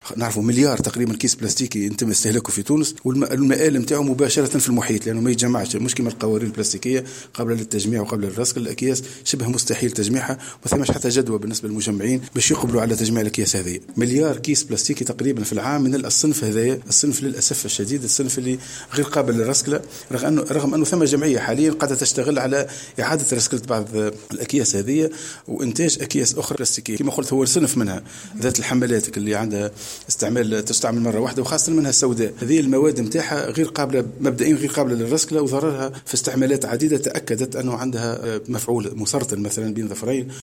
تصريح لمراسلتنا على هامش هذه التظاهرة